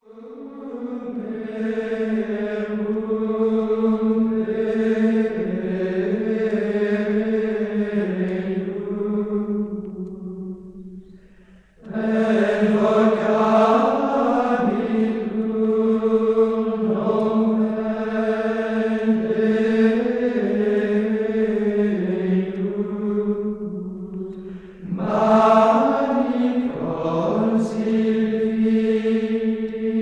Introit: Puer natus est (mode VII)